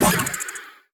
Monster_06_Attack.wav